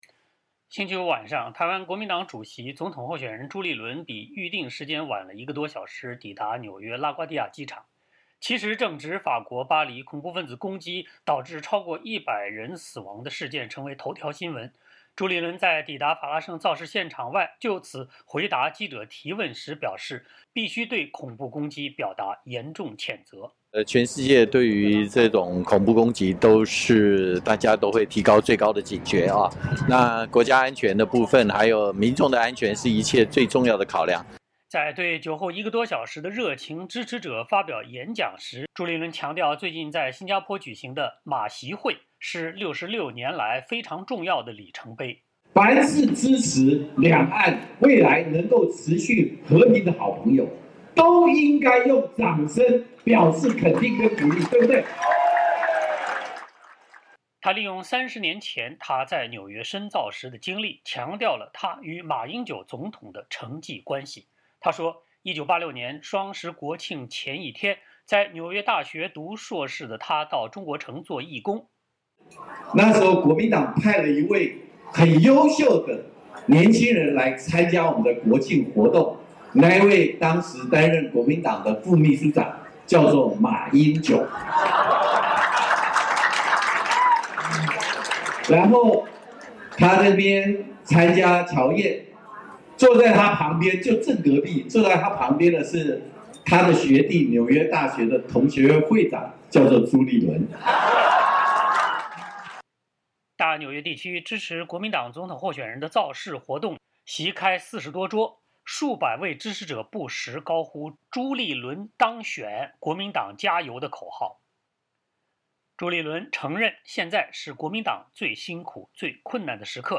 大纽约地区支持国民党总统候选人的造势活动席开40多桌，数百位支持者不时高呼“朱立伦‘冻蒜’(当选)、国民党加油”的口号。